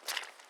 Water Walking 1_04.wav